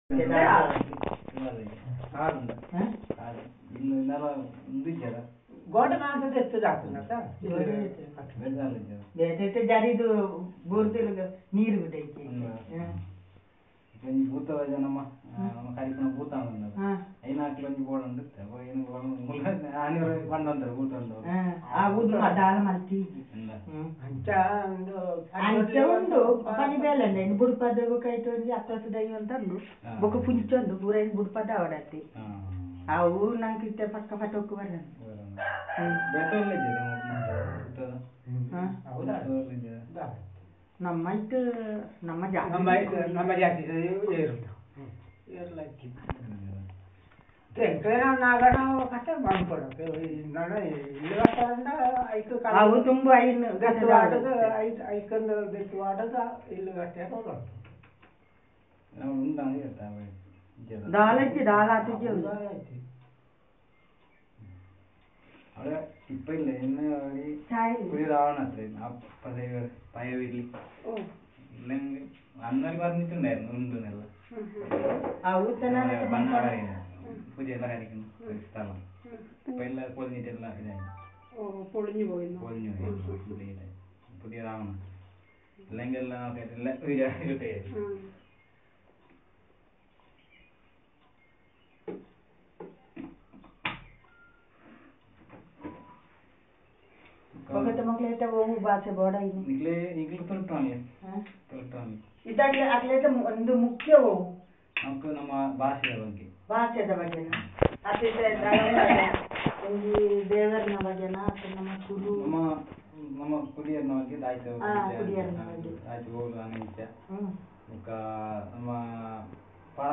The conversation about Language, mythology and caste